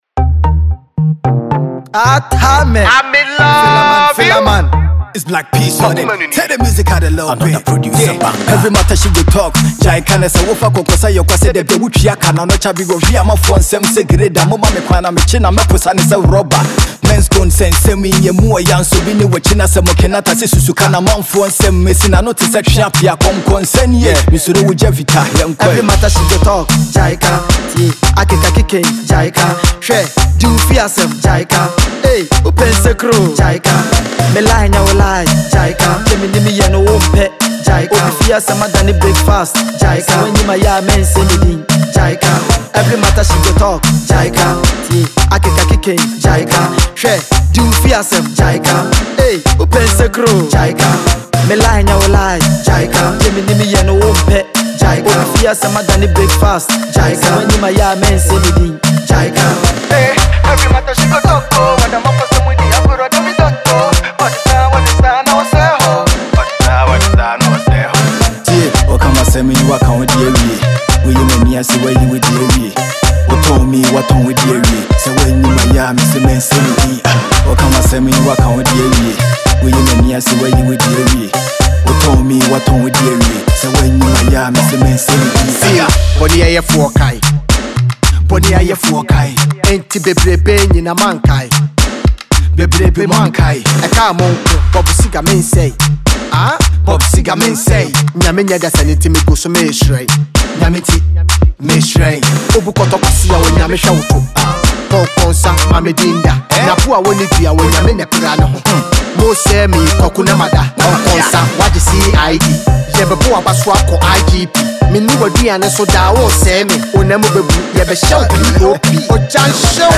Hiplife